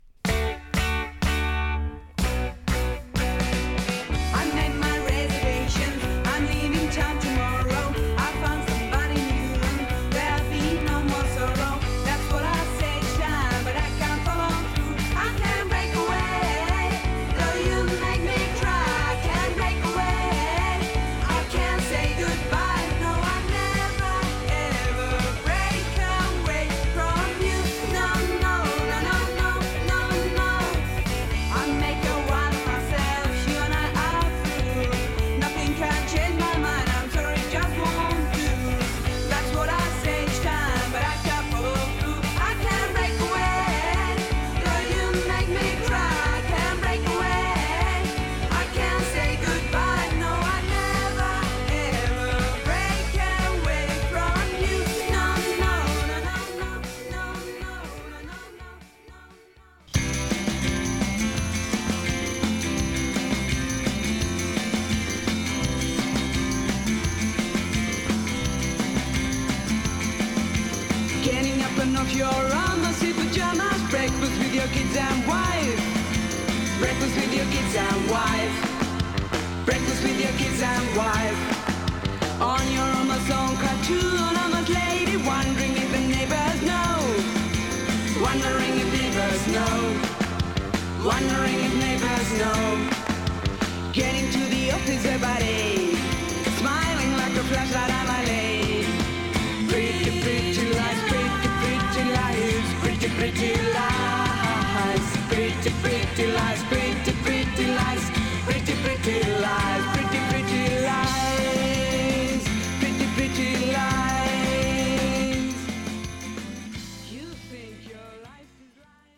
フィンランド産フィメールネオアコバンド。